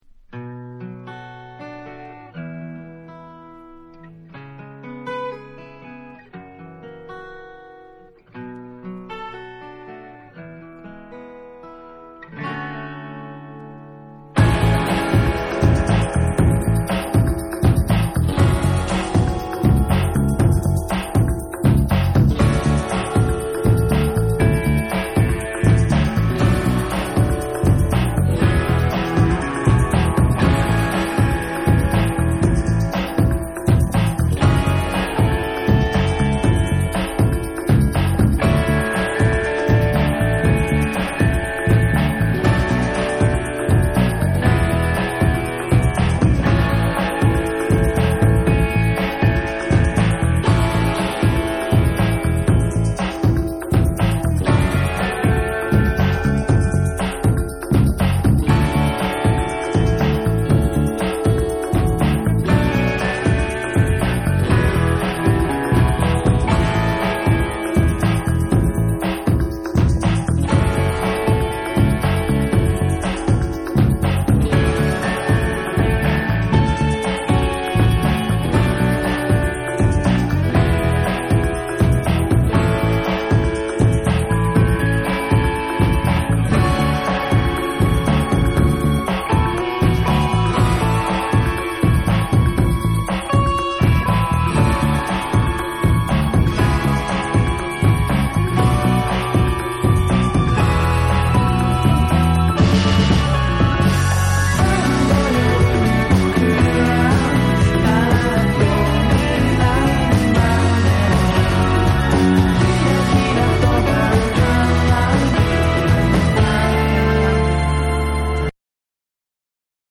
パーカッションの効いたダンサブルなリズムにメロディアスなヴォーカルが絡み